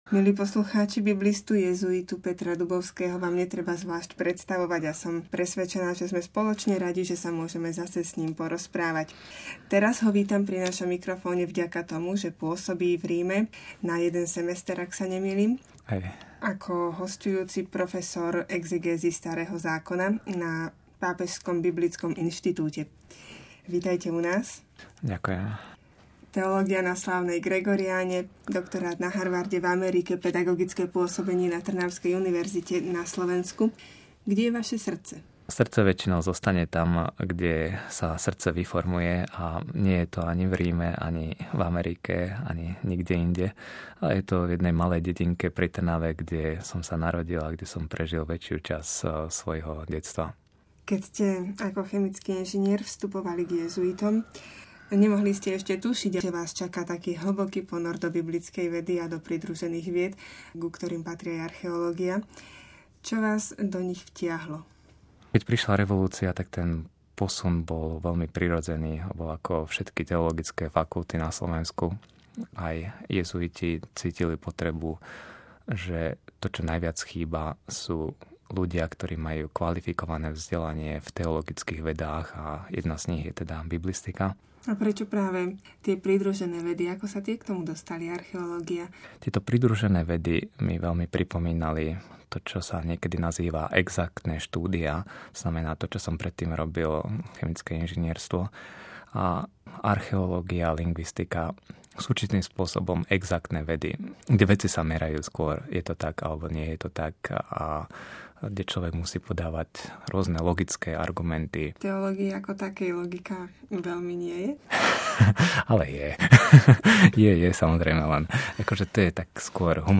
Rozhovor týždňa